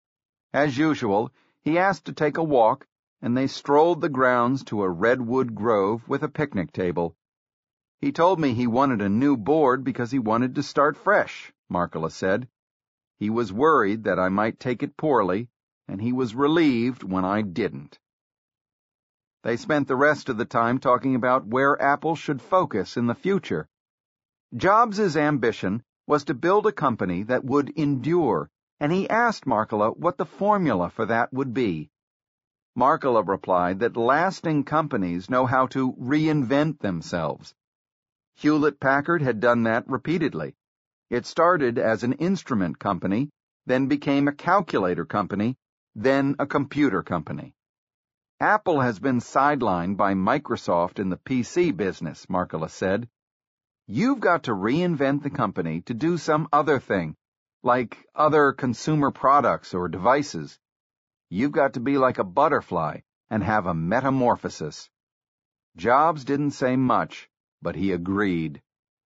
在线英语听力室乔布斯传 第392期:阿梅里奥出局(13)的听力文件下载,《乔布斯传》双语有声读物栏目，通过英语音频MP3和中英双语字幕，来帮助英语学习者提高英语听说能力。
本栏目纯正的英语发音，以及完整的传记内容，详细描述了乔布斯的一生，是学习英语的必备材料。